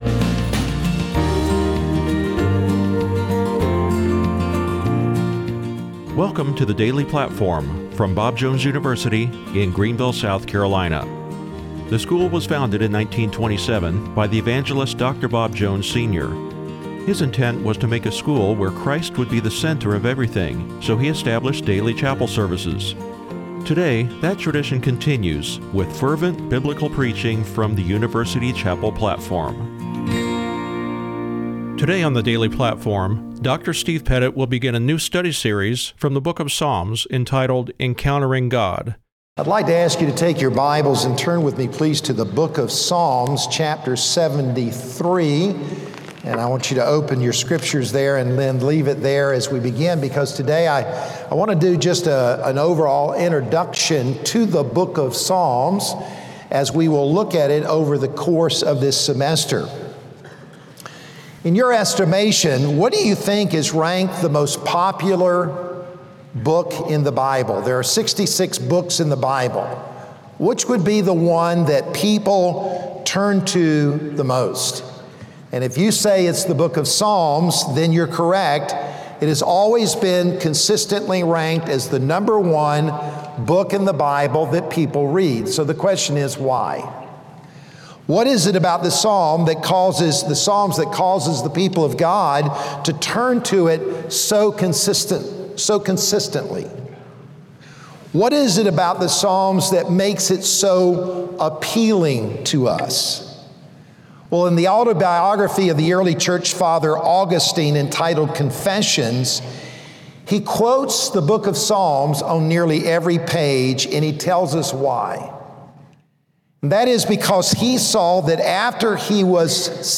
a chapel series entitled “Encountering God,” with a message from the book of Psalms.